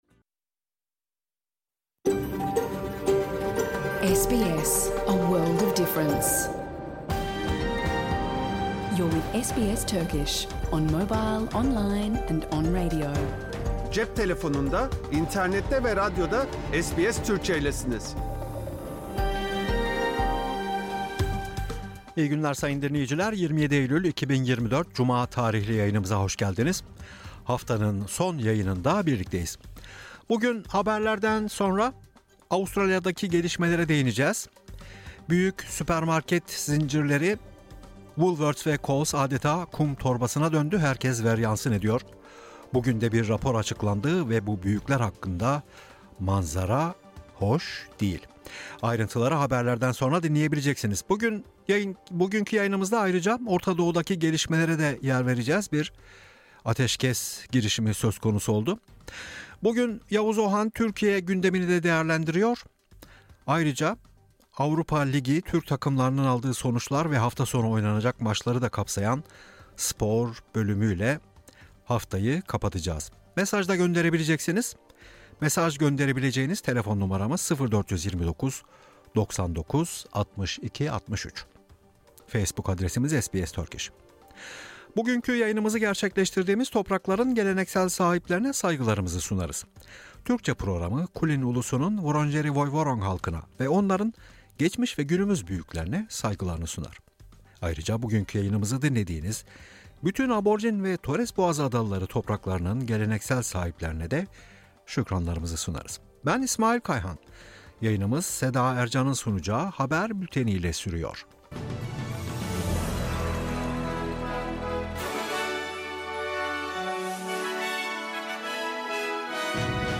Hafta içi Salı hariç hergün her saat 14:00 ile 15:00 arasında yayınlanan SBS Türkçe radyo programını artık reklamsız, müziksiz ve kesintisiz bir şekilde dinleyebilirsiniz.